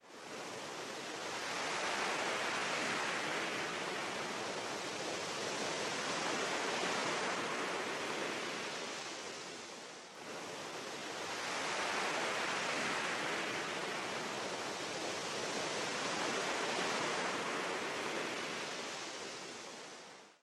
OLAS
OLAS - Tono movil - EFECTOS DE SONIDO
Tonos gratis para tu telefono – NUEVOS EFECTOS DE SONIDO DE AMBIENTE de OLAS
Olas.mp3